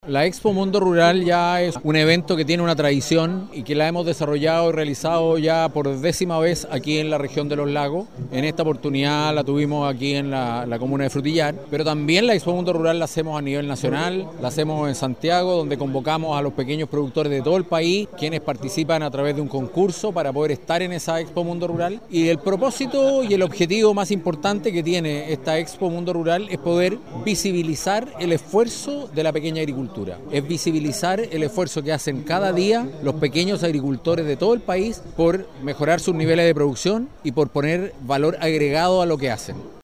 En su discurso, el director nacional de INDAP, Carlos Recondo, señaló que la importancia de realizar estas ferias de la Agricultura Familiar Campesina, busca potenciar la producción y economía a los pequeños agricultores de todo el país.